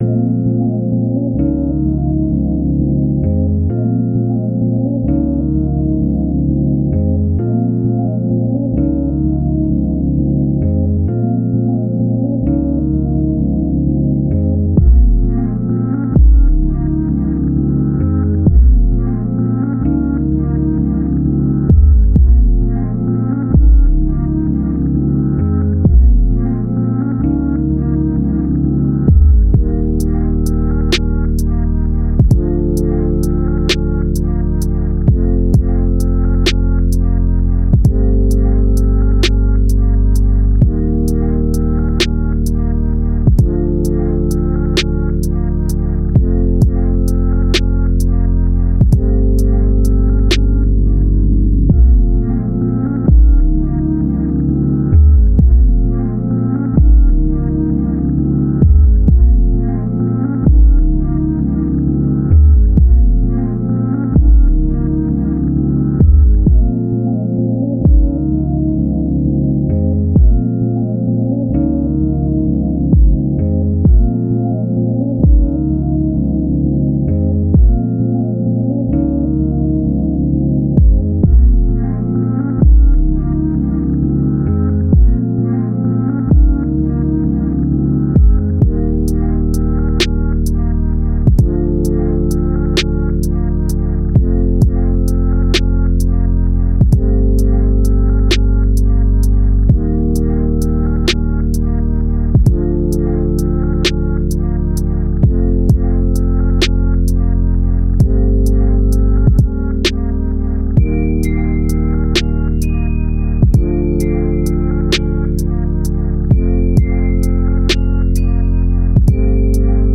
R&B – Lovely – Type Beat
Key: Bbm
130 BPM